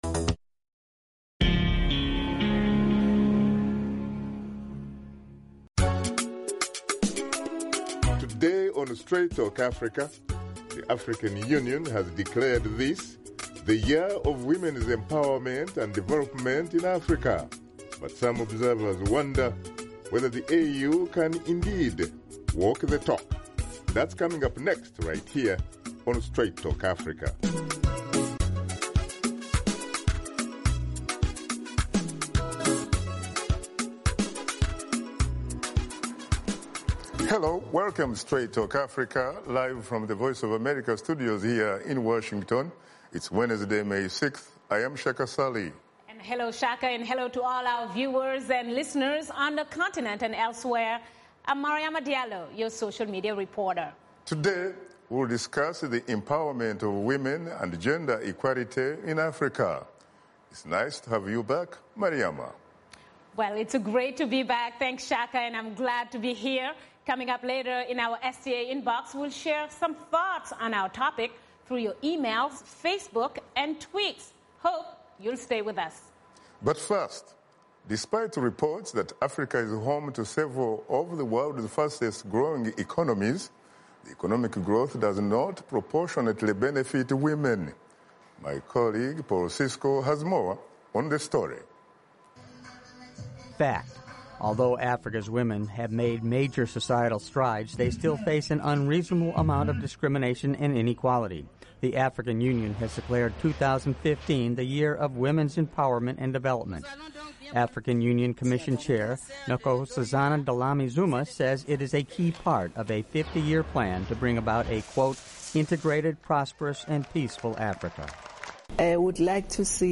Host Shaka Ssali and his guests discuss women’s empowerment and gender equality on the continent as the African Union declares 2015 the Year of the Woman.